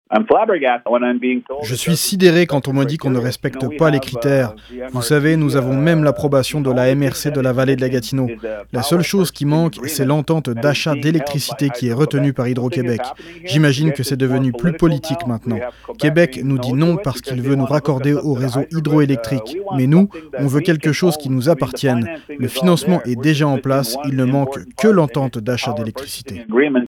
Le chef de bande de Lac-Barrière, Casey Ratt, partage sa stupéfaction devant le refus du gouvernement du Québec de donner le feu vert au projet d’électrification par biomasse de la communauté